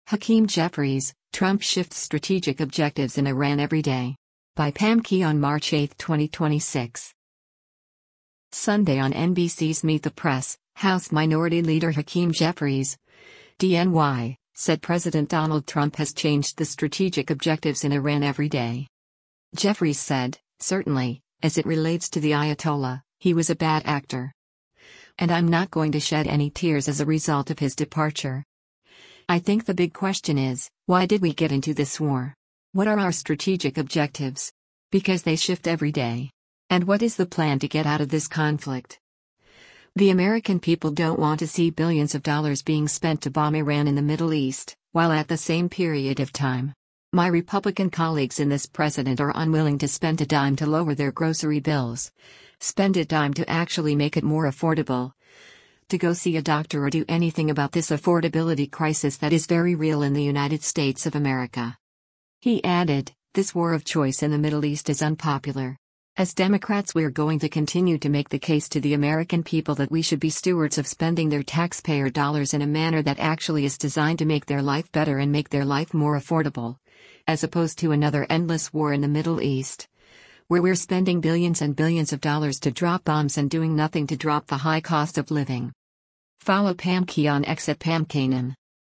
Sunday on NBC’s “Meet the Press,” House Minority Leader Hakeem Jeffries (D-NY) said President Donald Trump has changed the strategic objectives in Iran every day.